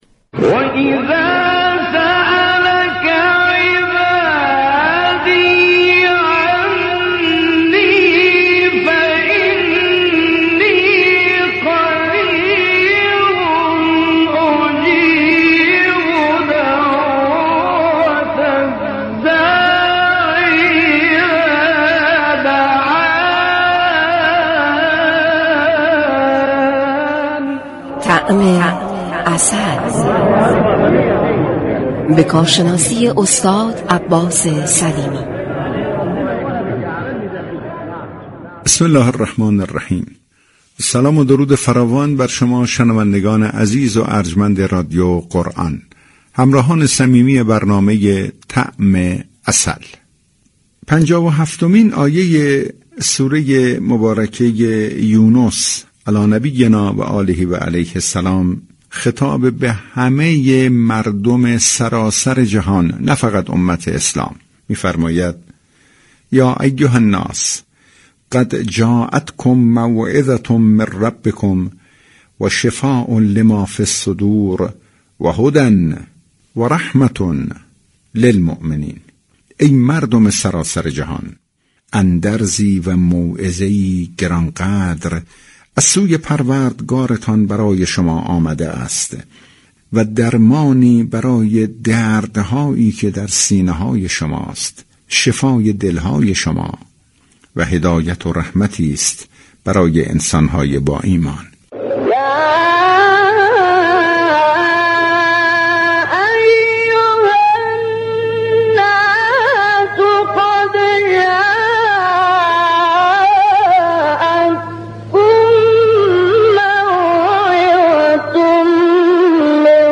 مجله صبحگاهی "تسنیم" با رویكرد اطلاع رسانی همراه با بخش هایی متنوع، شنبه تا پنجشنبه از شبكه ی رادیویی قرآن به صورت زنده تقدیم شنوندگان می شود.